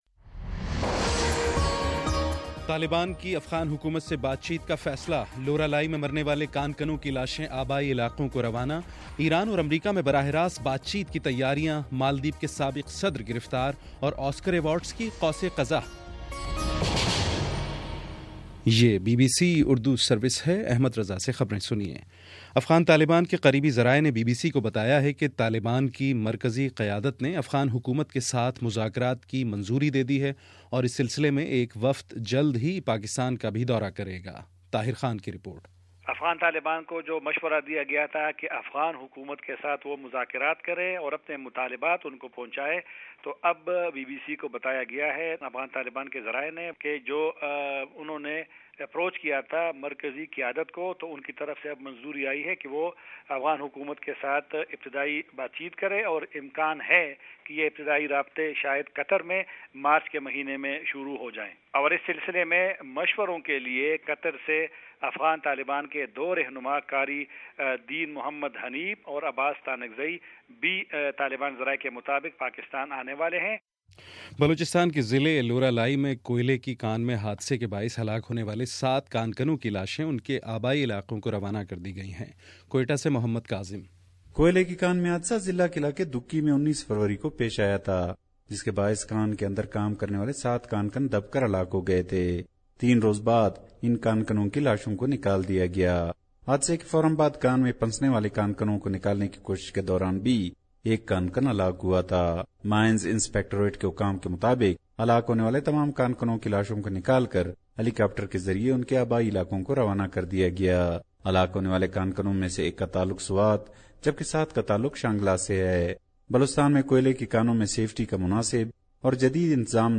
فروری22: شام سات بجے کا نیوز بُلیٹن